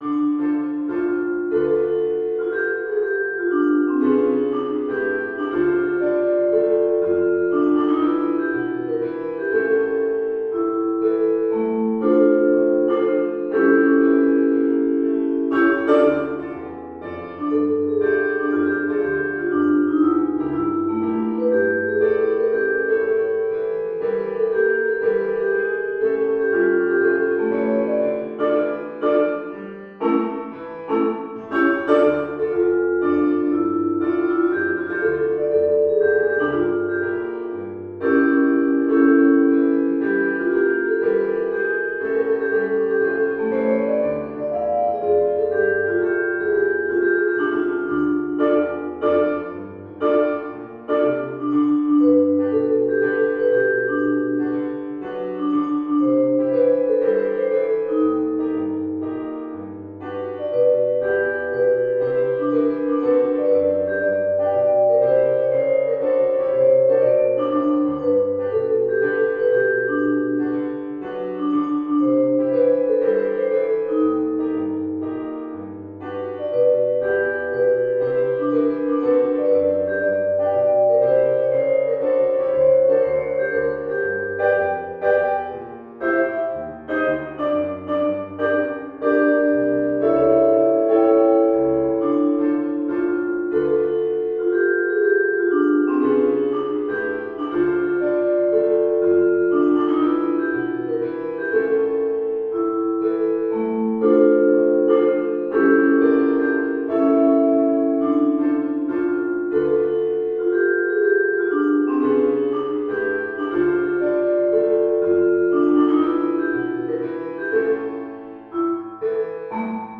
Genre: Duet for Vibraphone & Piano
Vibraphone
Piano